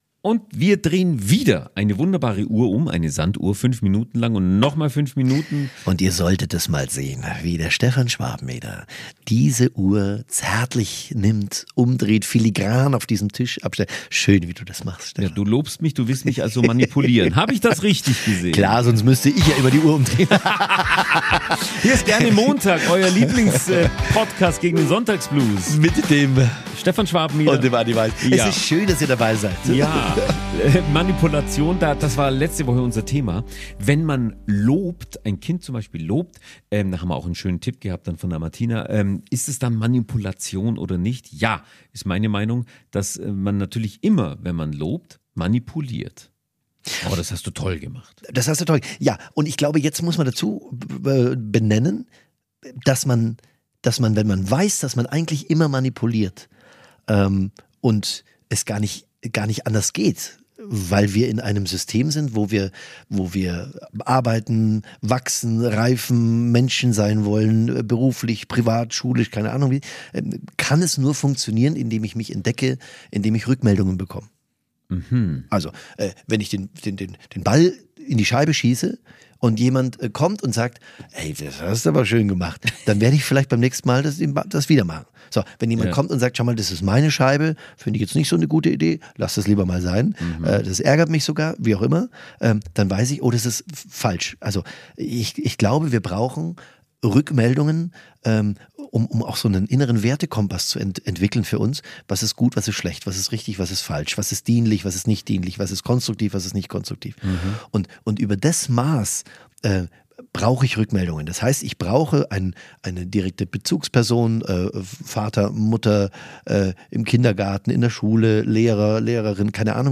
Verpasst nicht diese spannende Diskussion!